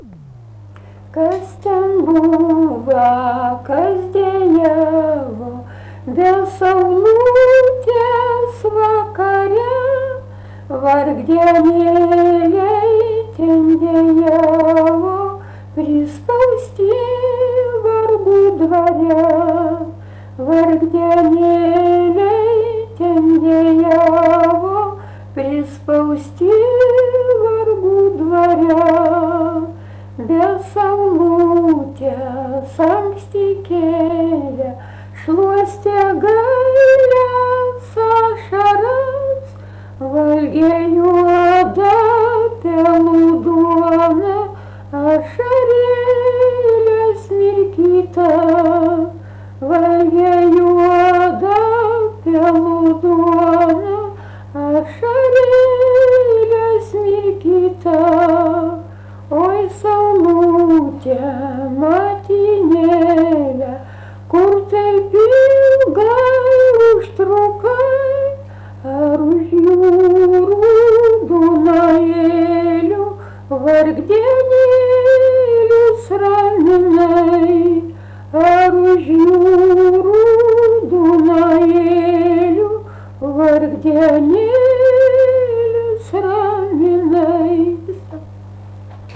Dalykas, tema daina
Erdvinė aprėptis Jūžintai
Atlikimo pubūdis vokalinis